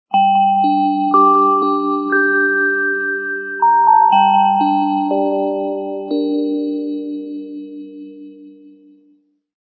Church_bells.ogg